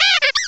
cry_not_glameow.aif